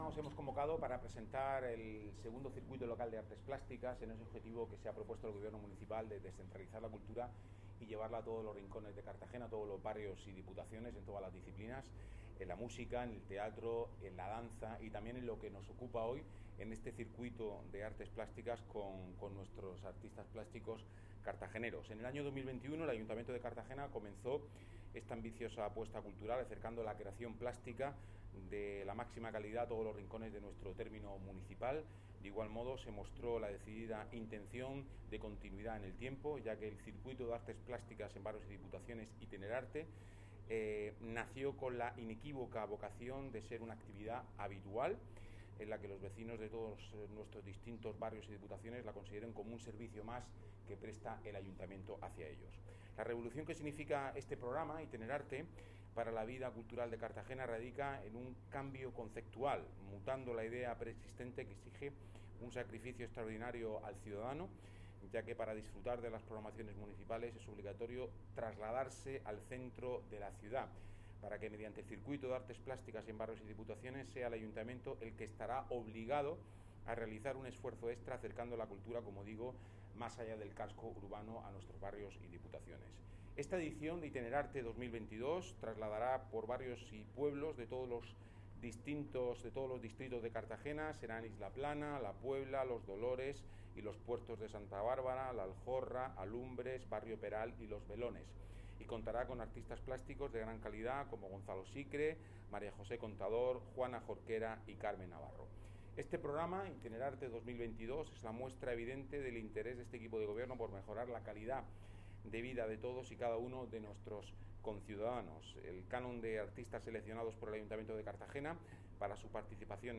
Presentación 'Itenerarte' 2022